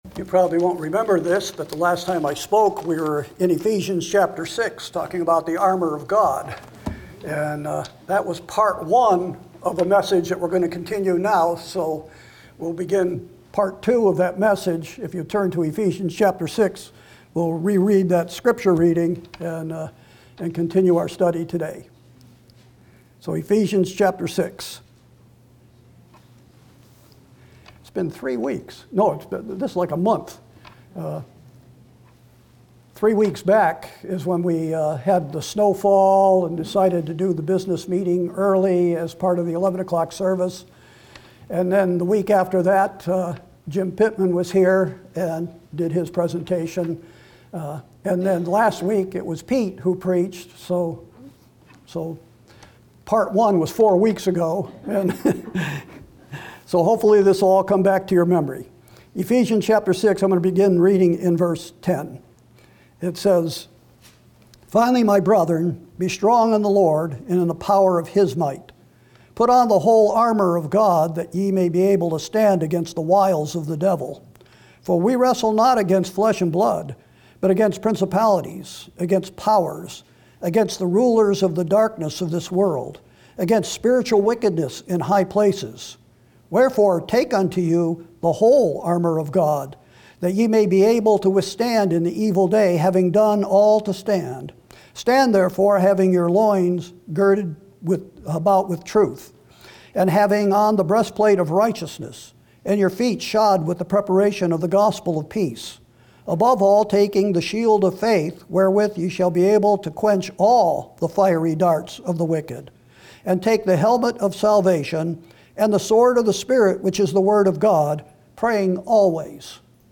Sermons & Single Studies